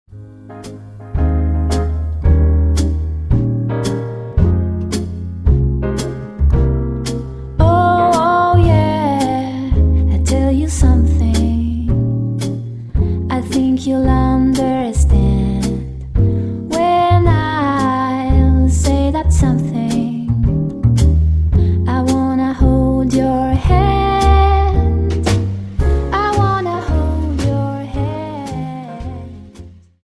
Dance: Slowfox 28